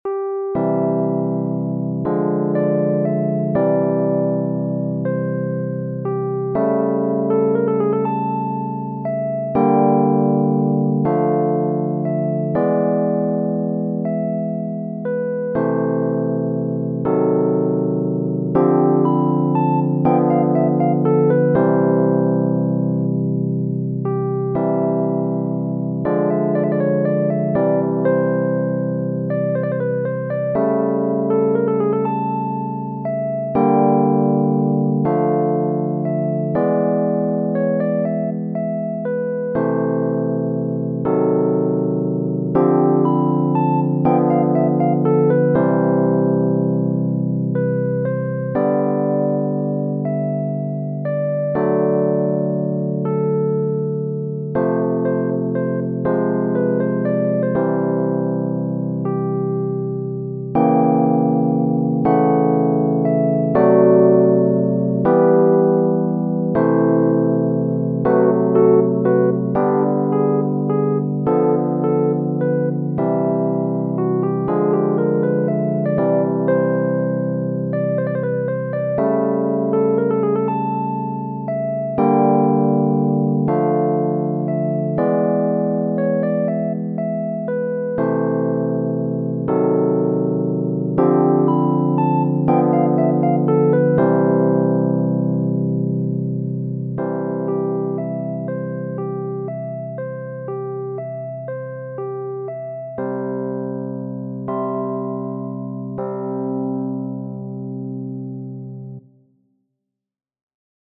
Chopin, F. Genere: Romantiche Il "Notturno 2, Op. 9" è una composizione per pianoforte di Fryderyk Chopin, datata fra il 1829 e il 1830.